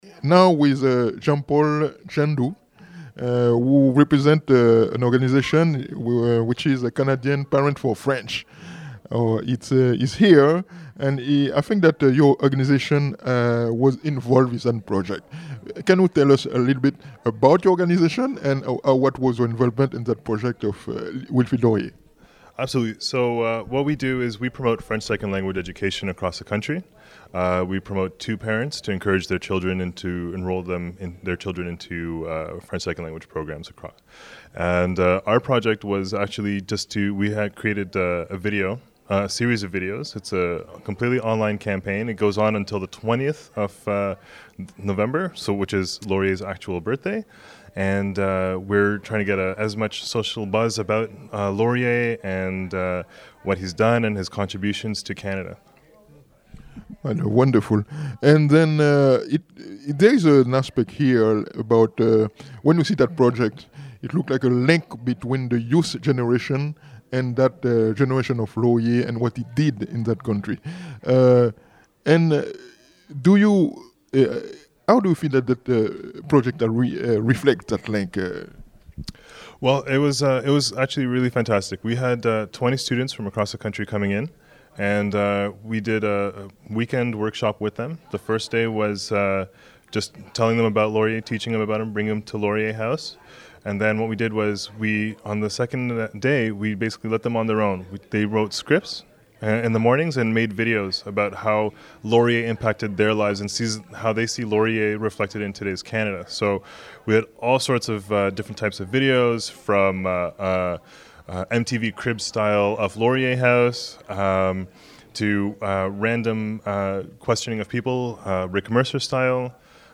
Recording Location: Ottawa
Type: Interview
320kbps Stereo